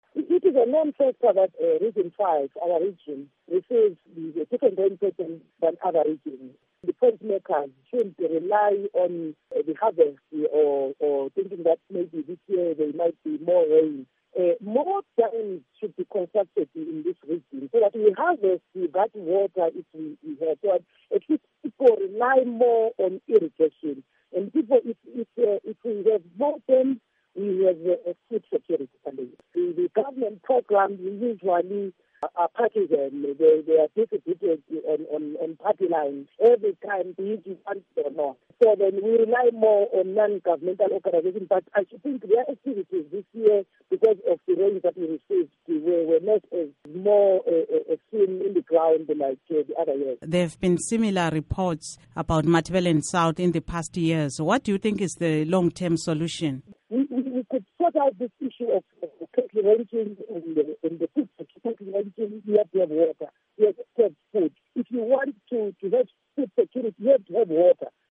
Interveiw